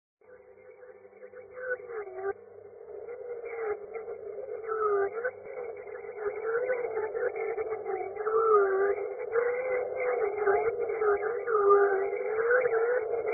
polar bear from 2026 sound Meme Sound Effect